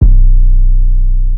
GC - Southside 808.wav